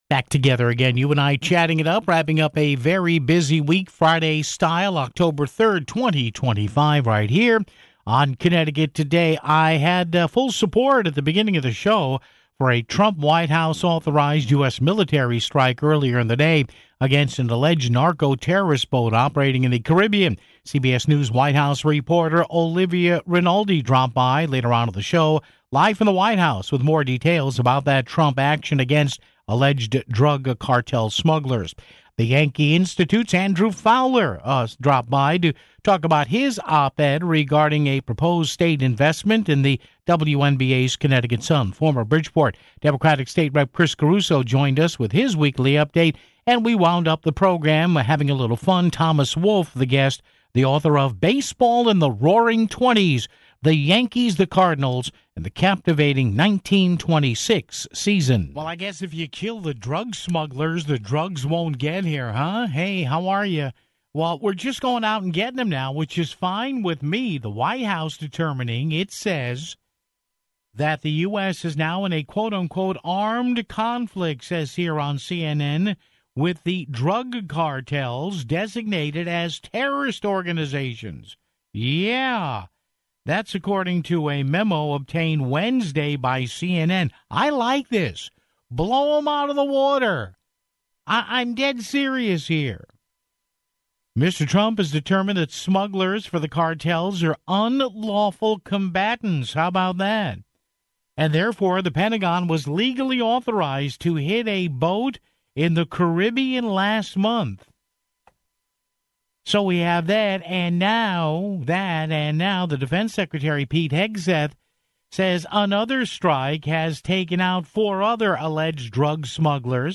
Former Bridgeport Democratic State Rep. Chris Caruso joined us with his weekly update (29:11).